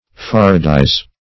Faradize \Far"a*dize\, v. t. [imp. & p. p. Faradized; p. pr. &
/far'@?di:z/, v.